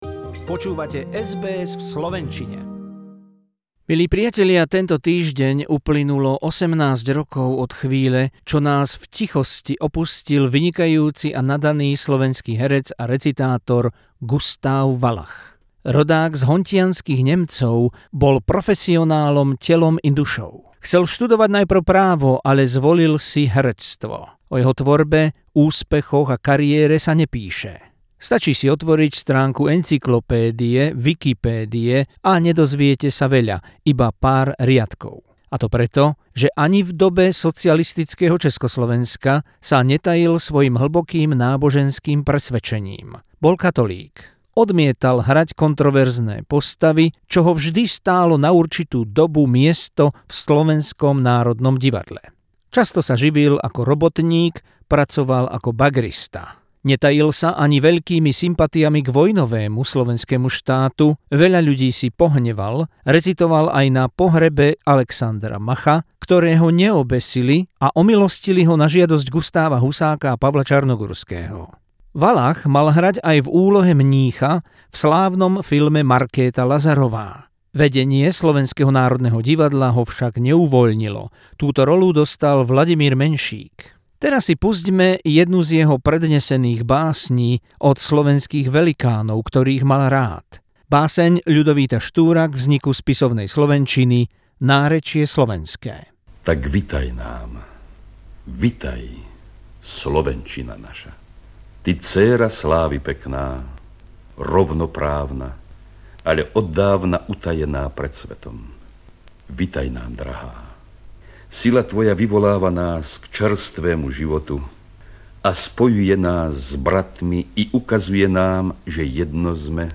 Feature about the great Slovak actor Gustav Valach, suppressed for his political and religious views, who had to work as a dredger for years.